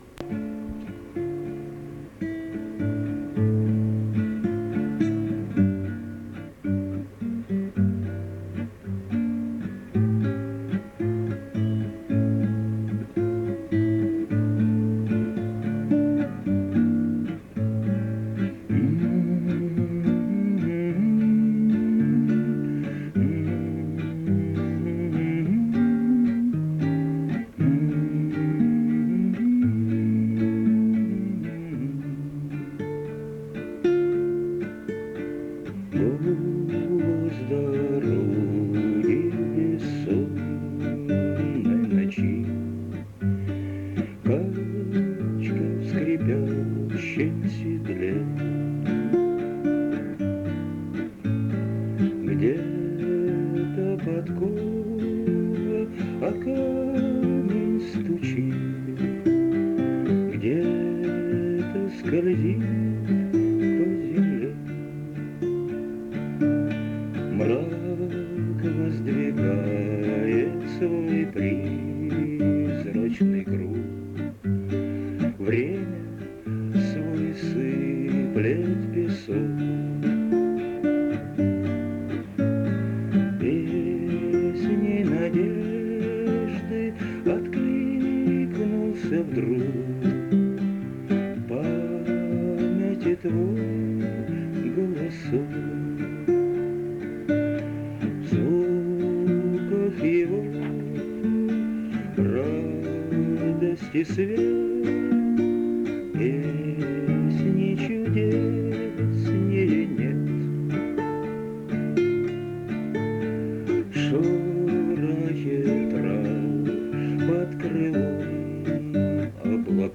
музыка, слова и исполнение